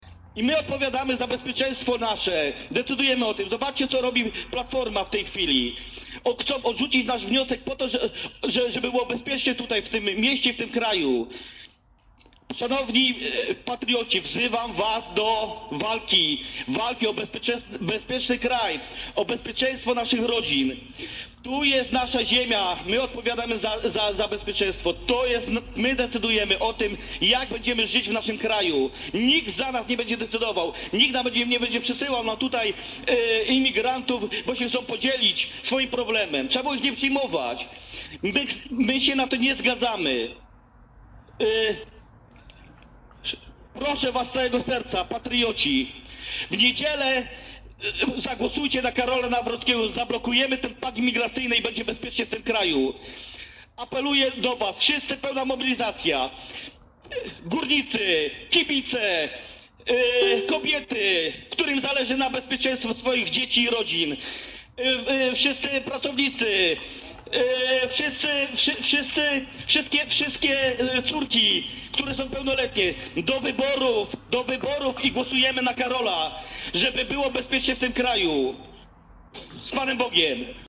Generalnie wystąpienie radnego miało bardzo emocjonalny charakter w trakcie długiej dyskusji, w której żadna ze stron nie wypadła dobrze.